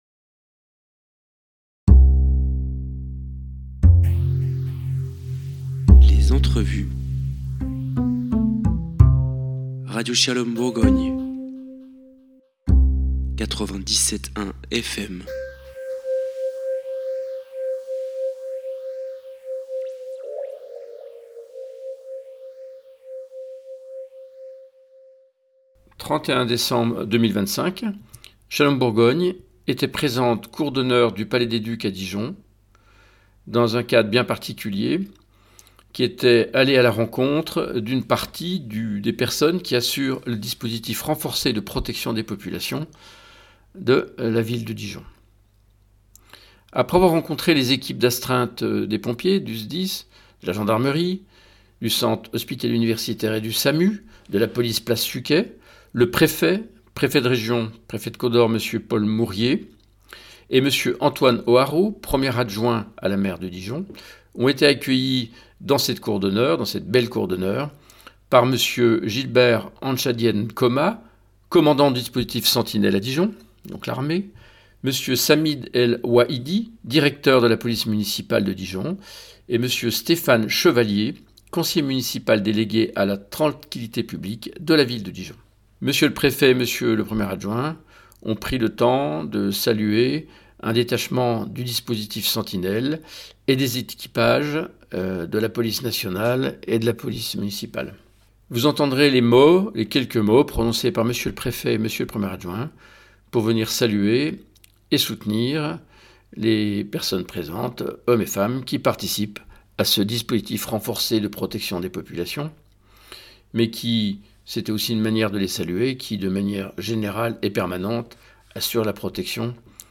Vous entendrez les mots prononcés par Monsieur le préfet et monsieur le
Reportage